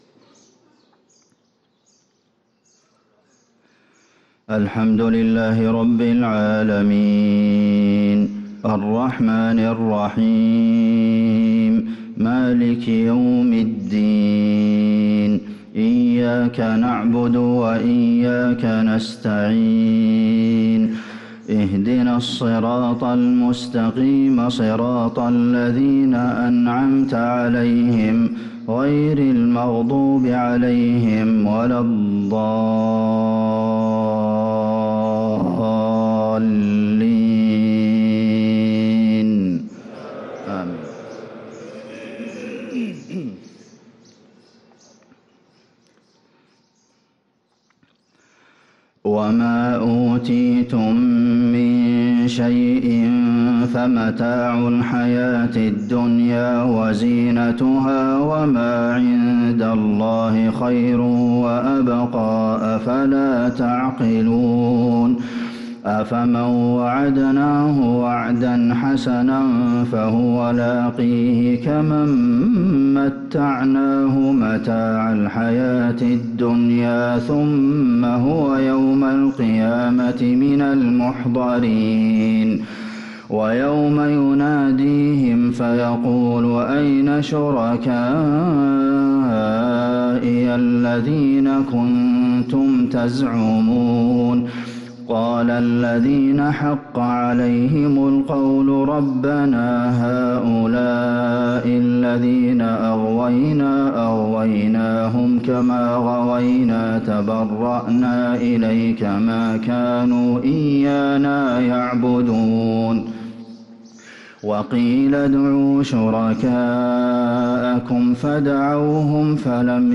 فجر الأربعاء 17 رجب 1444هـ من سورة القصص | Fajr prayer from Surat Al-Qasas 8-2-2023 > 1444 🕌 > الفروض - تلاوات الحرمين